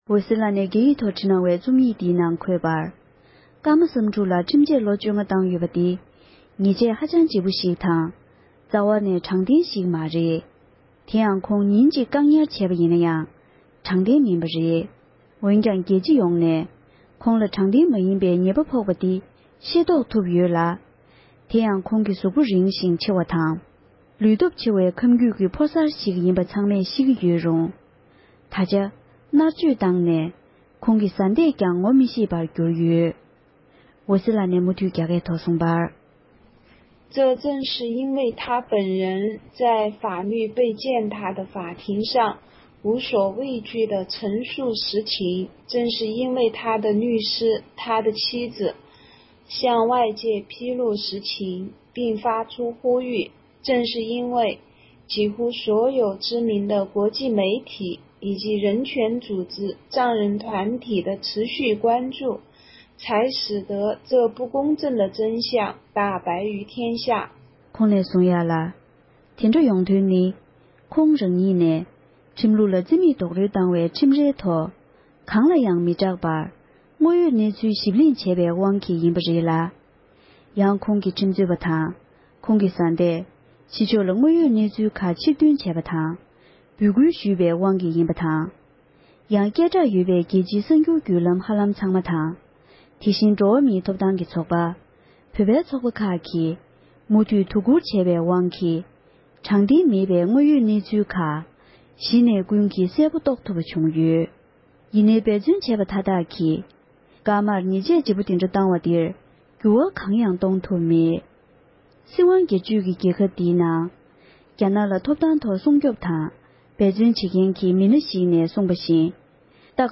སྒྲ་ལྡན་གསར་འགྱུར། སྒྲ་ཕབ་ལེན།
ཕབ་བསྒྱུར་དང་སྙན་སྒྲོན་ཞུས་པར་གསན་རོགས༎